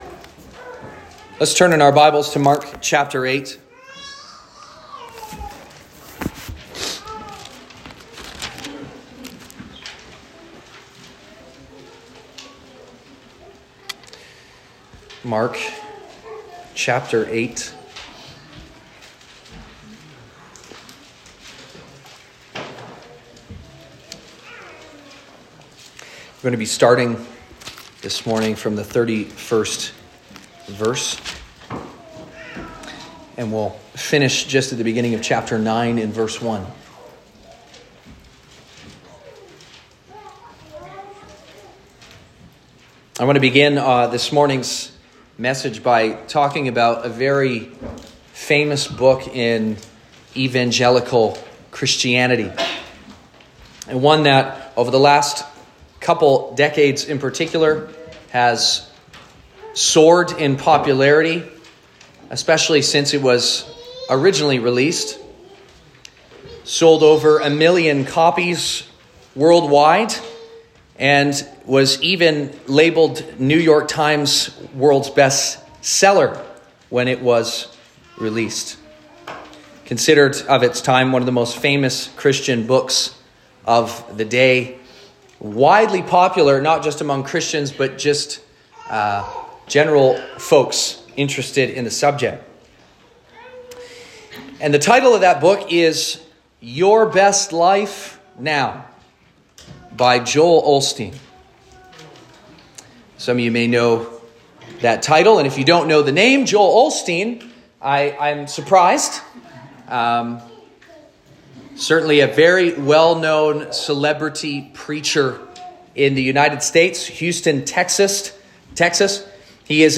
Sermons | Sonrise Community Baptist